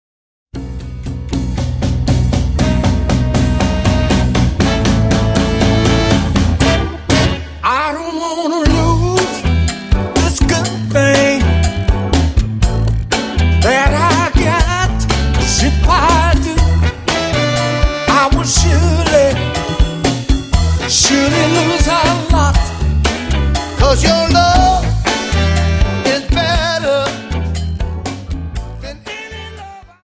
Dance: Cha Cha 30